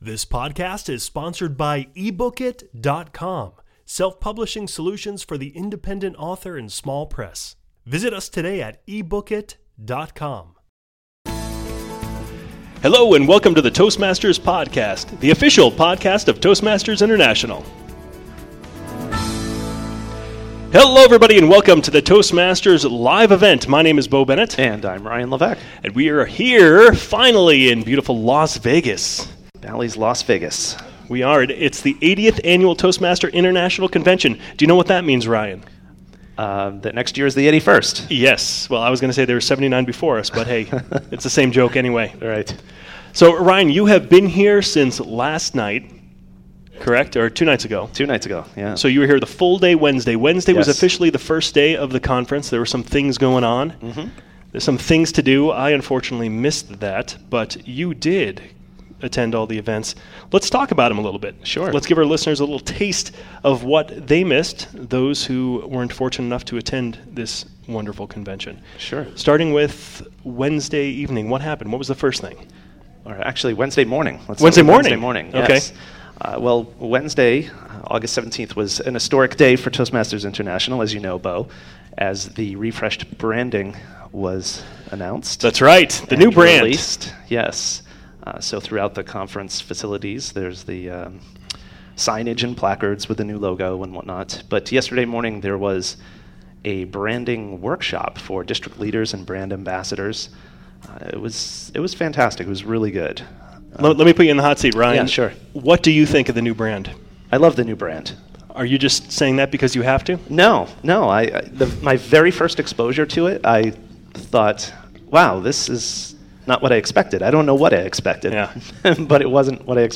#048: Toastmasters International 2011 Convention - Live Event | Toastmasters Podcast